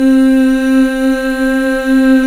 Index of /90_sSampleCDs/Club-50 - Foundations Roland/VOX_xFemale Ooz/VOX_xFm Ooz 1 S